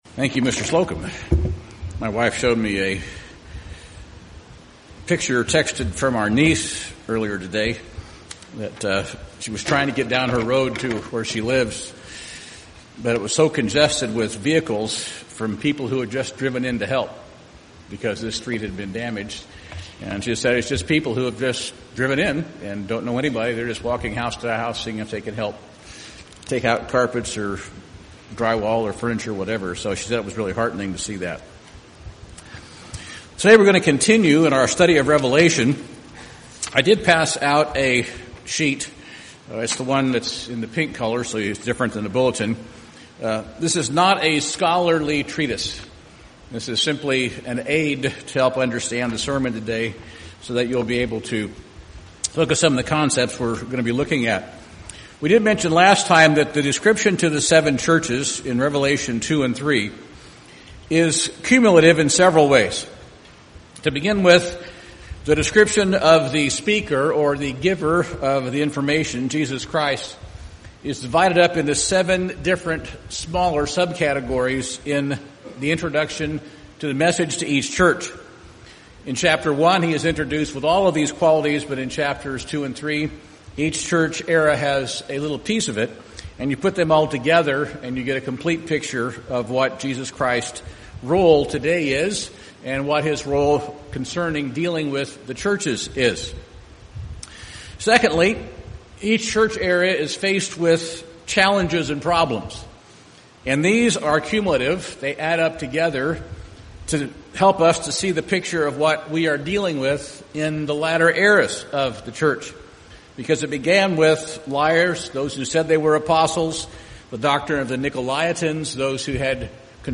This sermon is part 3 of the series on the Book of Revelation and summarizes the Sardis, Philadelphia, and Laodicean churches.